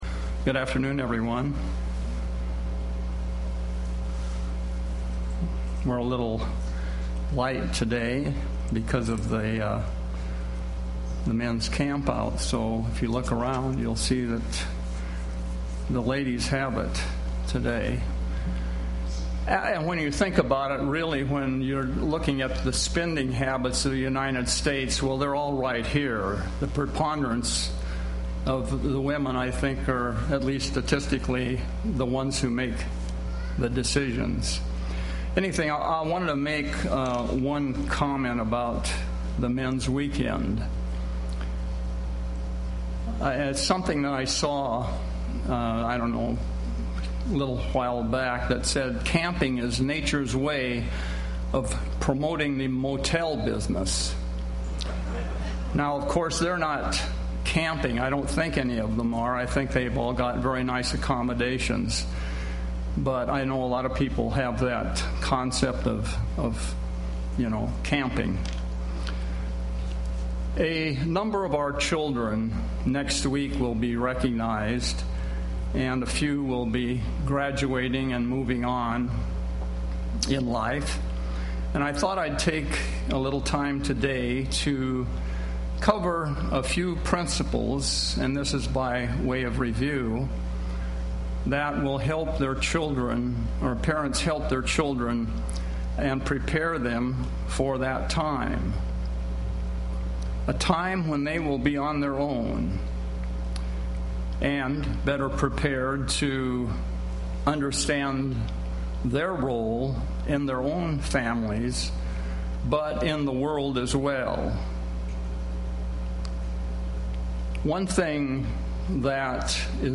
Given in Seattle, WA
Print UCG Sermon